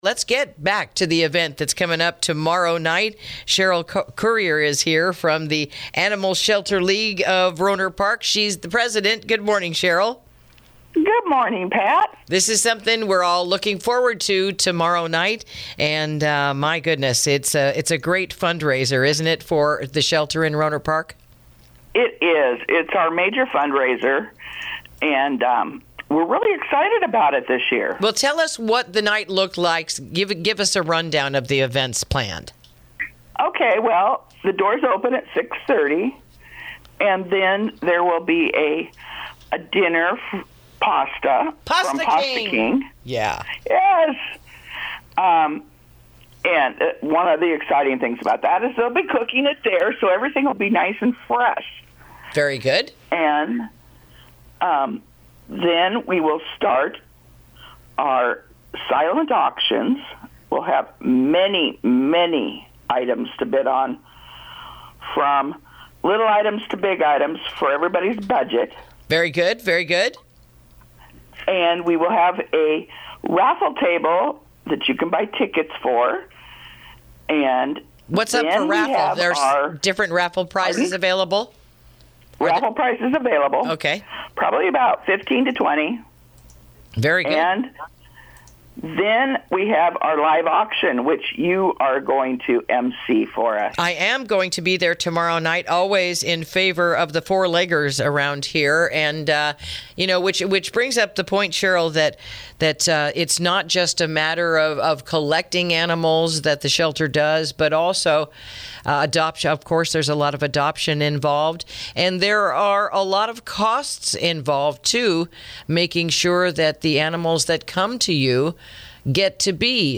INTERVIEW: Support Our Furry Friends At the Bark After Dark Fundraiser This Saturday